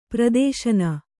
♪ pradēśana